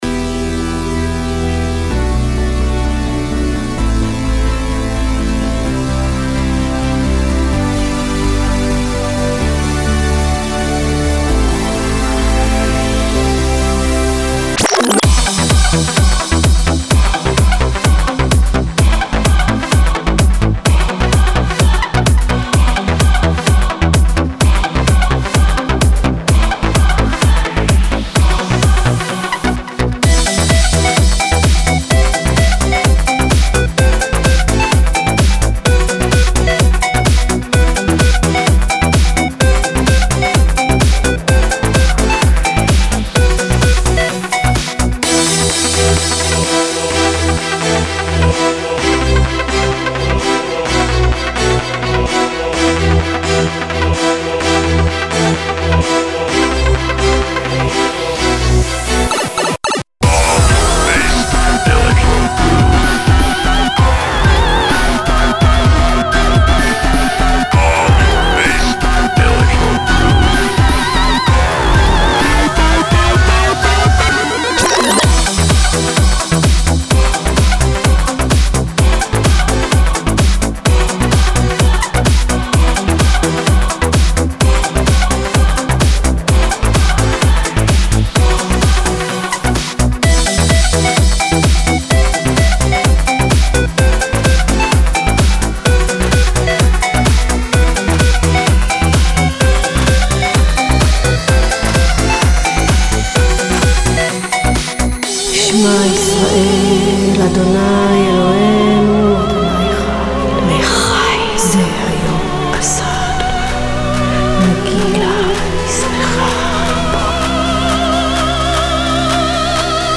Файл в обменнике2 Myзыкa->DJ's, транс
Жанр: Dance/Club